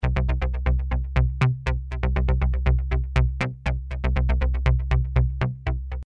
2 VCO 2 ADS enveloppe 1 LFO 1VCF 24db low pass 1VCA 1 noise generator